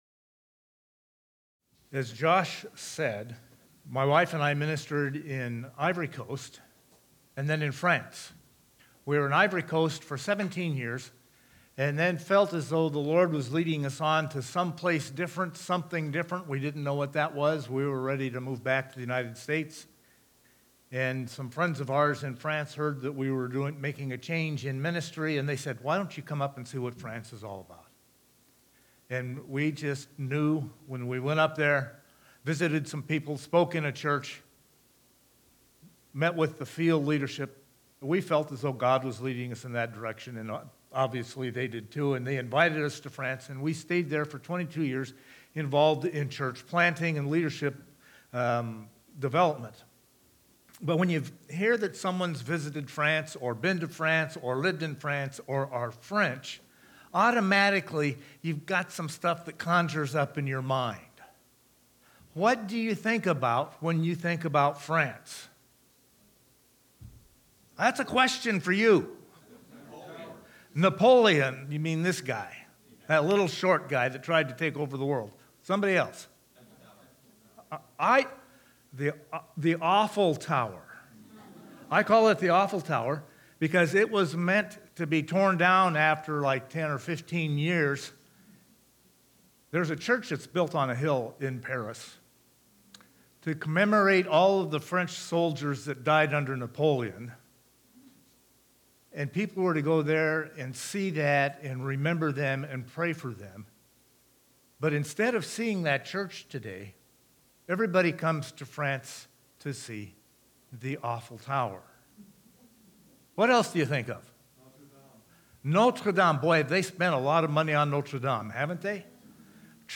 Special guest sermon